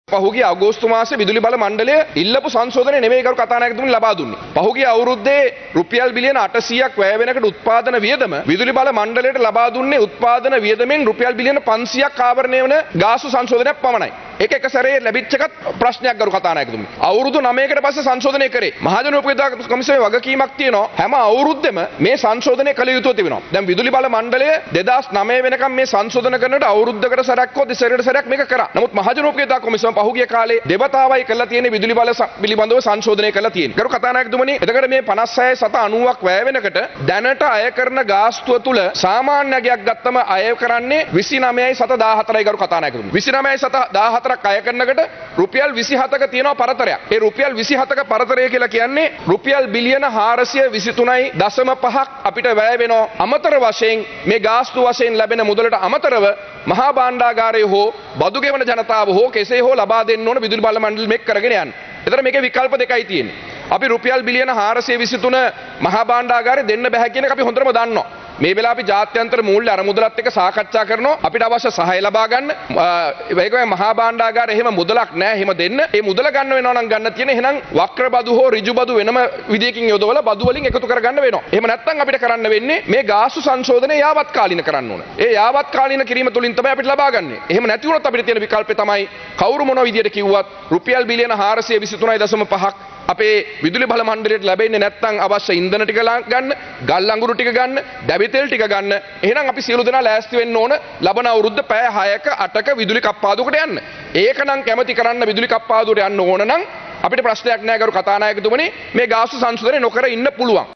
දැනට විදුලිය උත්පාදනය සඳහා වැඩි වශයෙන් ඇත්තේ තාප විදුලි බලාගාර බවයි අමාත්‍යවරයා අද පාර්ලිමේන්තුවේ දී පැවසුවේ.
ඔහු මේ බව පැවසුවේ අද පාර්ලිමේන්තුවේ පැවති අය වැය විවාදයට එක්වෙමින්.